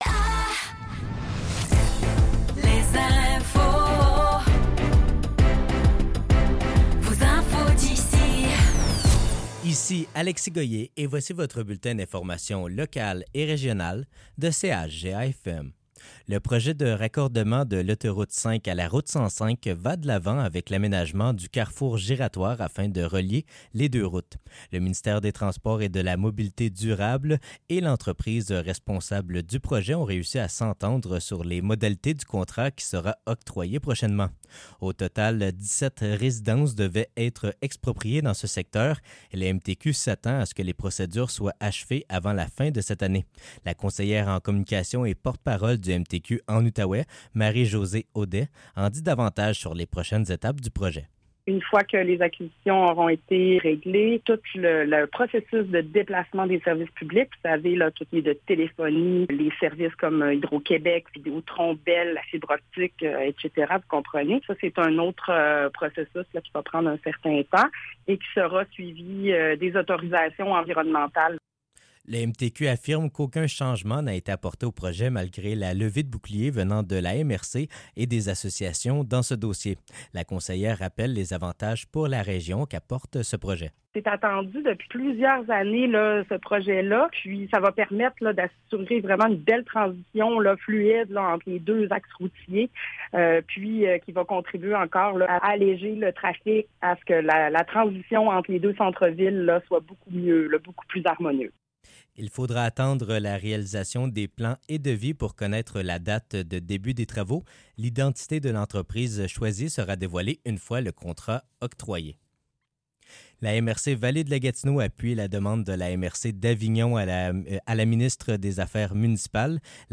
Nouvelles locales - 16 mai 2024 - 15 h